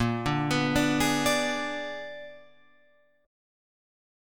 A#7b9 chord